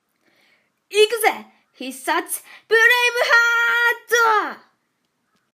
サンプルボイス 熱血 【少年】